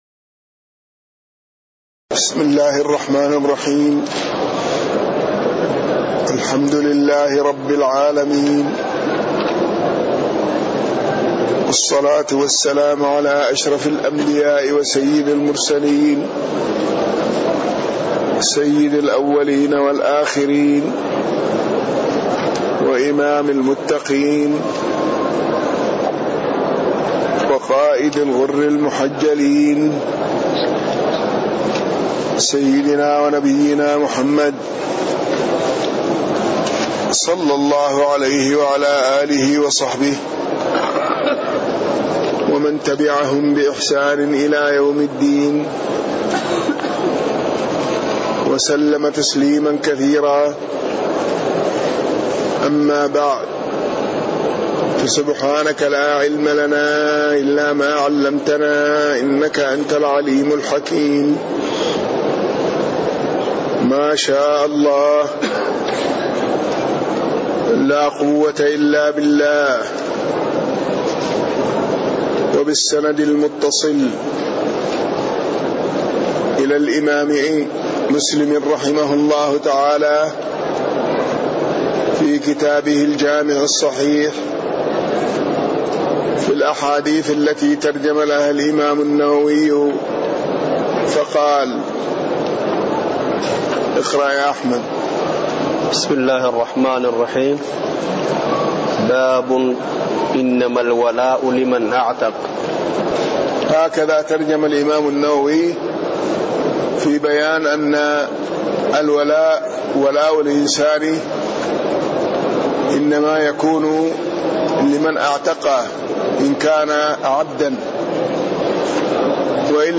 تاريخ النشر ١١ شوال ١٤٣٤ هـ المكان: المسجد النبوي الشيخ